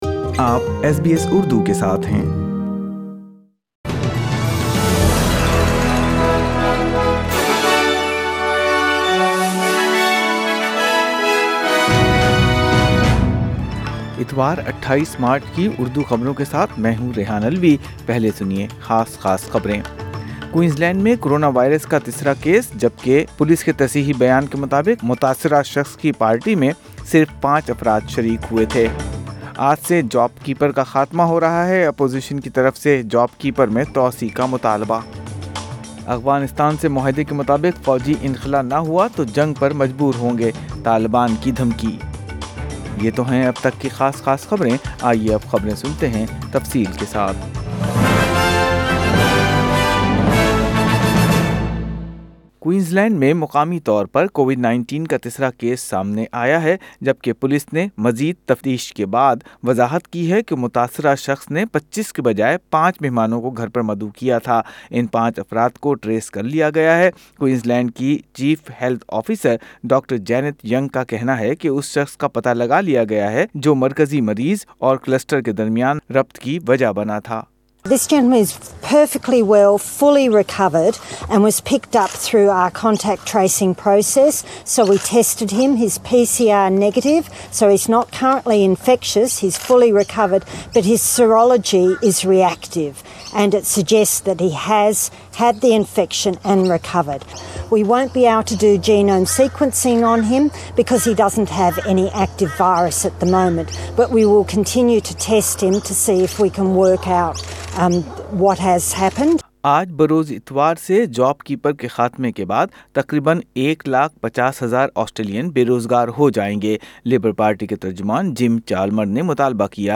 Urdu News Sun 28 Mar 2021